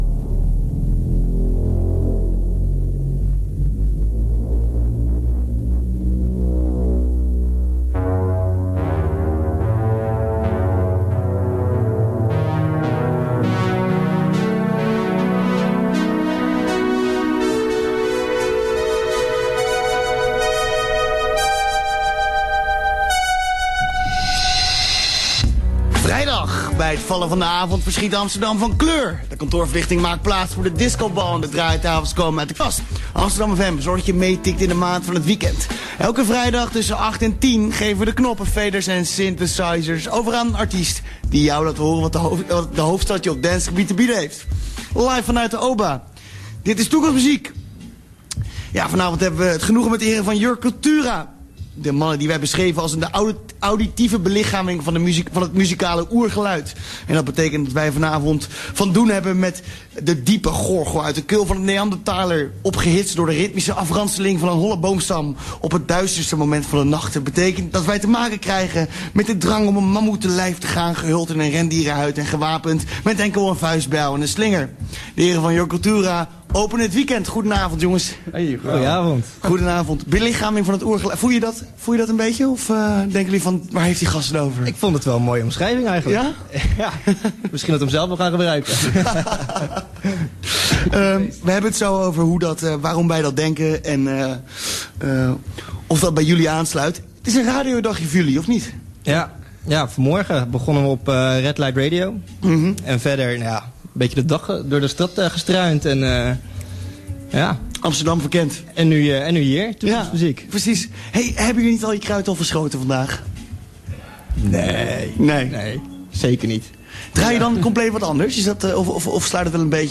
Alweer een tijd geleden was het trio Yør Kultura bij de mannen van Toekomstmuziek over de vloer. Zij spraken over hun muzikale invloeden – die van overal over de wereld stammen – over hun manier van produceren en natuurlijk over hun muziek.